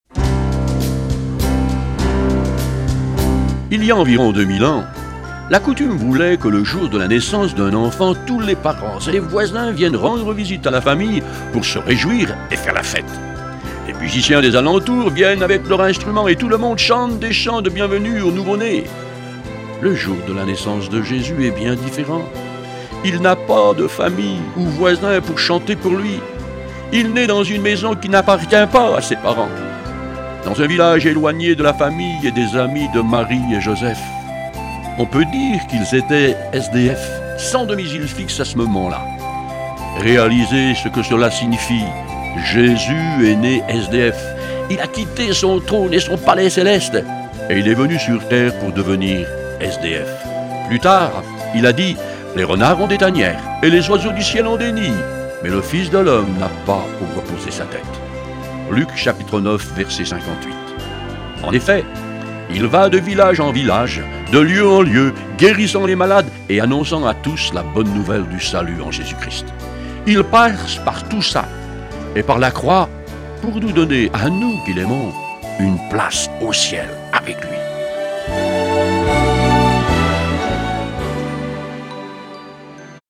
Une série de méditations pour le mois de Décembre
Version audio Phare FM :